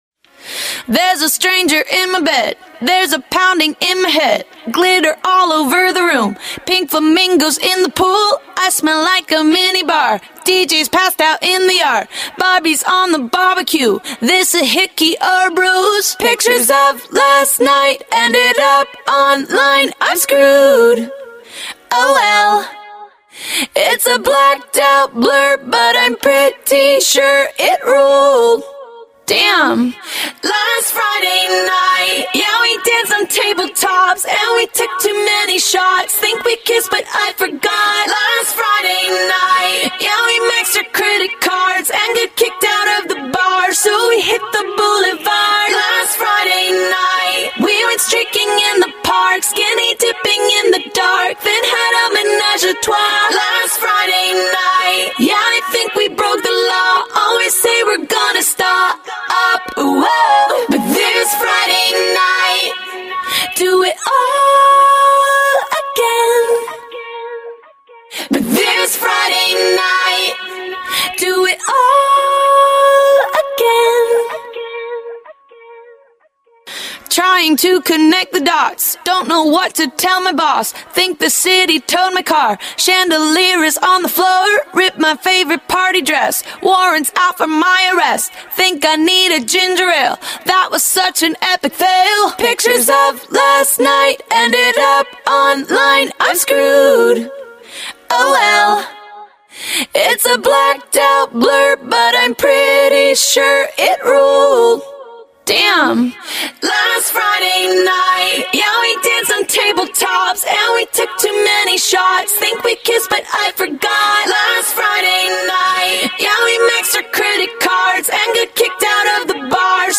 Категория: Скачать Зарубежные акапеллы